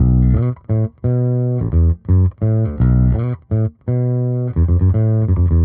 Index of /musicradar/dusty-funk-samples/Bass/85bpm
DF_JaBass_85-B.wav